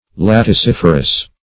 Search Result for " laticiferous" : The Collaborative International Dictionary of English v.0.48: Laticiferous \Lat`i*cif"er*ous\, a. [L. latex, laticis, a liquid + -ferous.]